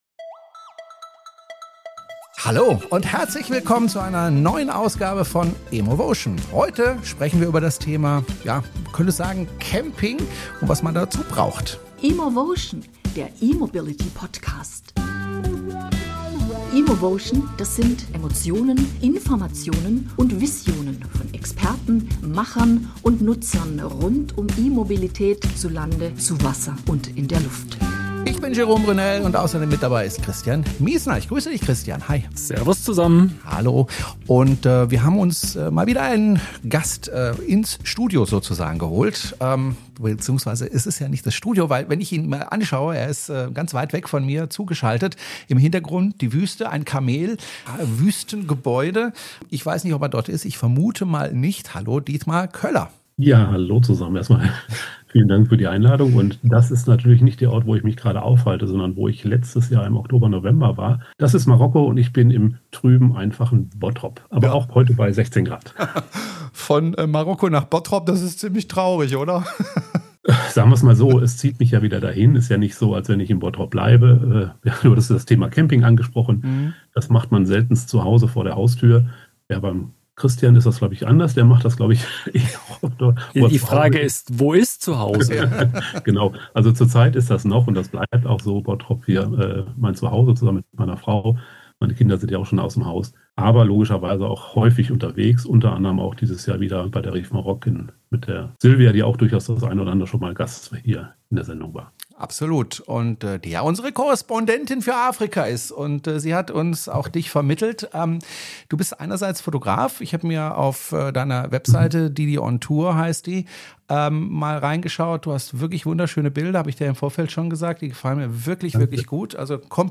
Auch dieses Mal haben wir einen interessanten Studiogast in unserem bescheidenen Podcast.